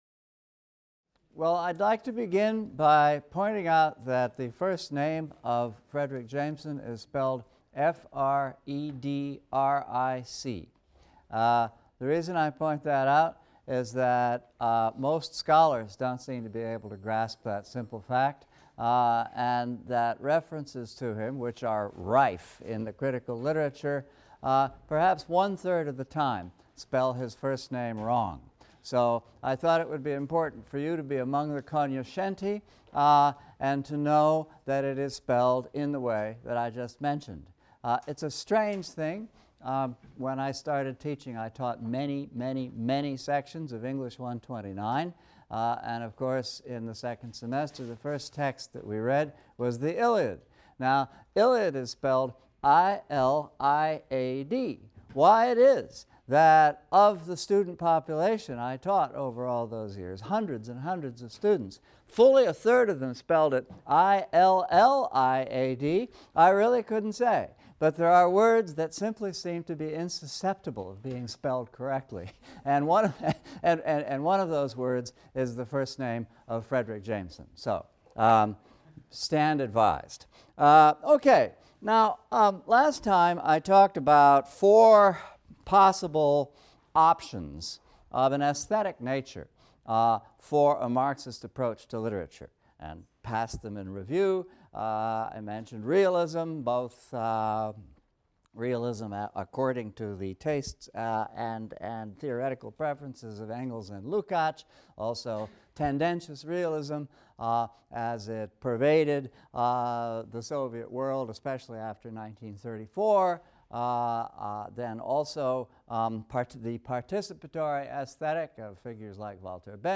ENGL 300 - Lecture 18 - The Political Unconscious | Open Yale Courses